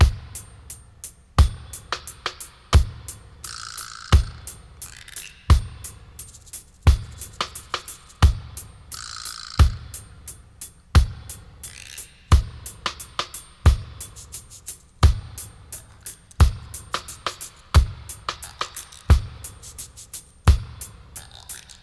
88-BRK+PERC-01.wav